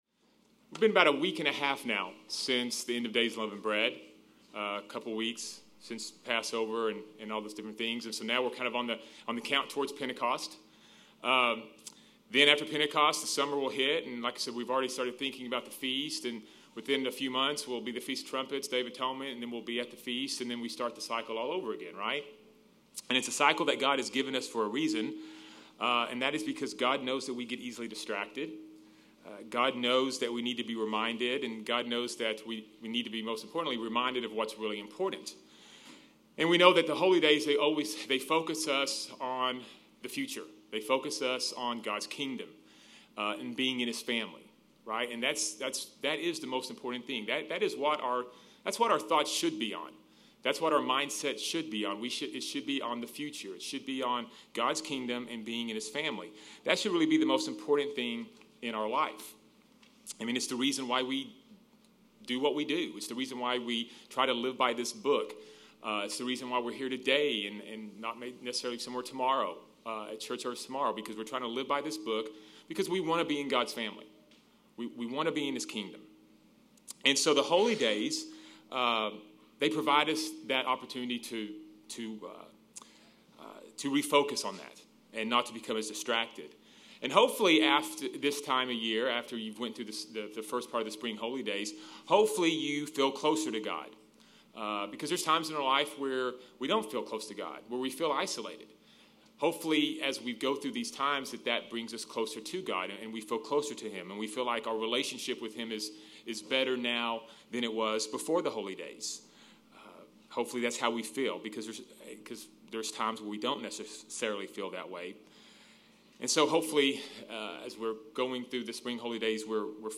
Sermons
Given in Fort Worth, TX